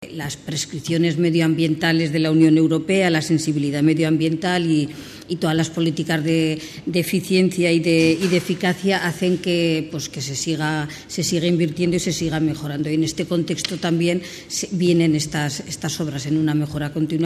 Por su parte, la concejala de Infraestructuras y Ciclo Integral del Agua, Lola Campos, resaltaba la mayor sensibilidad social social que existe respecto a los recursos y la sostenibilidad.